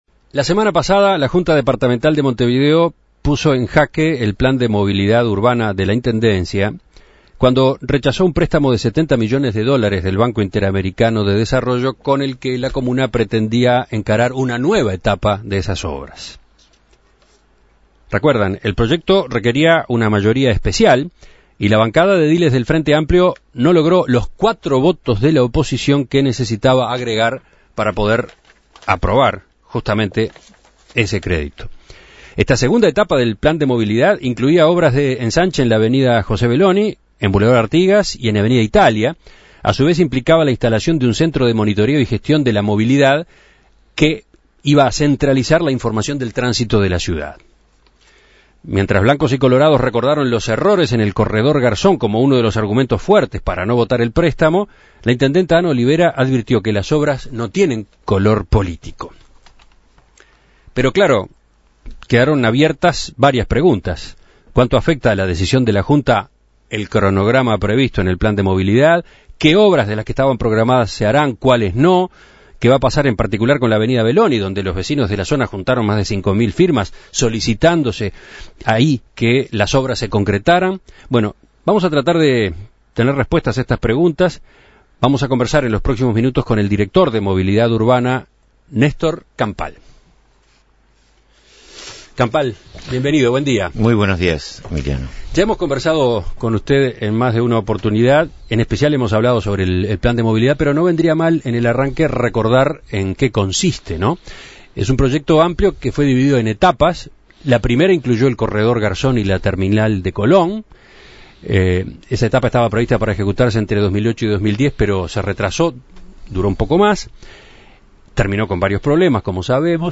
El préstamo que tenía planeado efectuar el Banco Interamericano de Desarrollo a la Intendencia de Montevideo quedó inhabilitado por resolución de la Junta Departamental. El oficialismo no logró alcanzar la totalidad de votos necesarios y el préstamo de 70 millones de dólares para la realización de obras quedó sin efecto. Para conocer qué impacto tiene esta resolución en el Plan de Movilidad Urbana de la Intendencia y cómo queda parada la administración de cara a su último año de gestión, En Perspectiva entrevistó al director de Movilidad Urbana de la IMM, Néstor Campal.